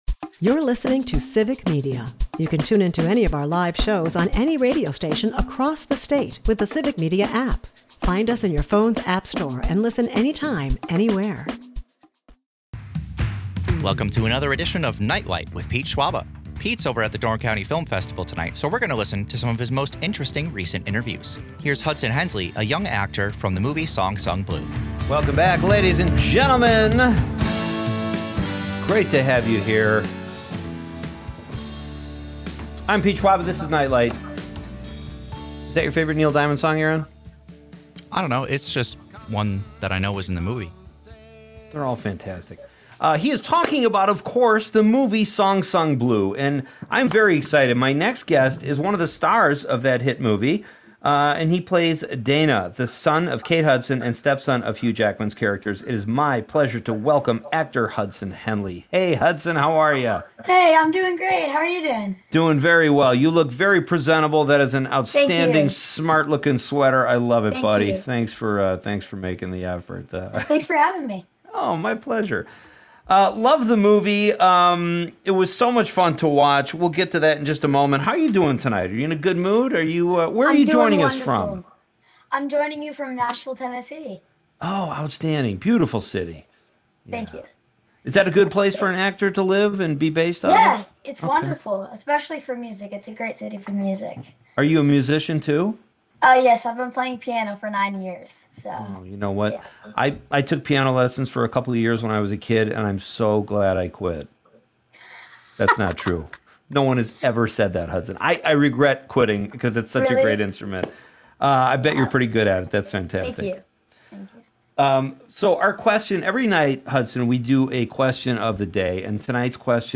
Tomorrow is Tartar Sauce Day, so for the Question of the Nite, we ask listeners about their favorite sauces. The right sauce in the right amount can make or break a dish, so we all shout out some of the greatest viscous liquids to put on foods.